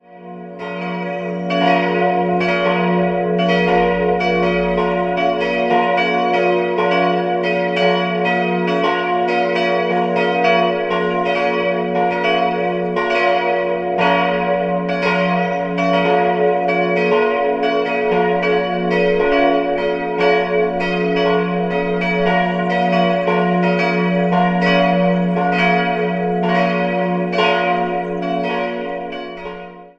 Für mehrere Jahrhunderte diente sie als Grablege der Fränkischen Hohenzollern. 3-stimmiges Geläut: fis'-h'-d'' Die Glocken wurden im Jahr 1952 von Friedrich Wilhelm Schilling in Heidelberg gegossen.